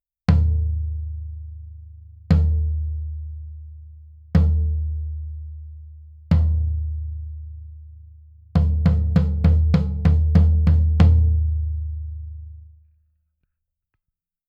実際の録り音
フロアタム
フロアタム.wav